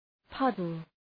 {‘pʌdəl}